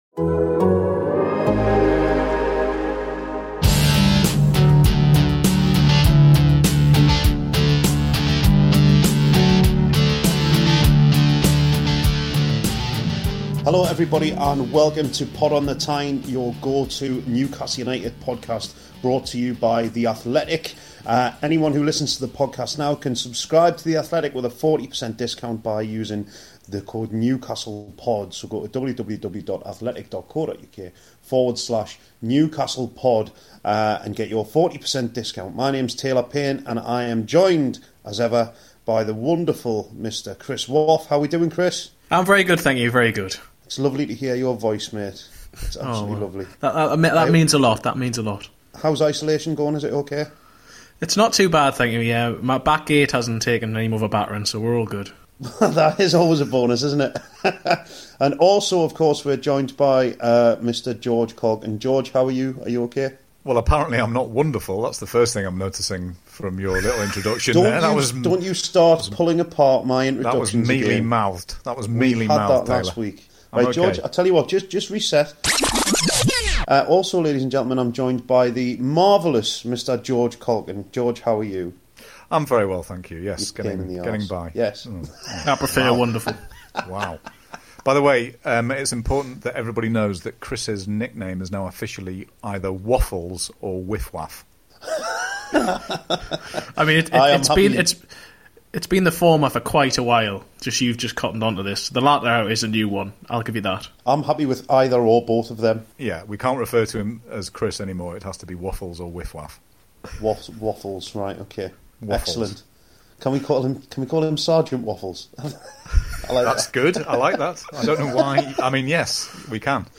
The Aaron Hughes Interview - 20:50 4.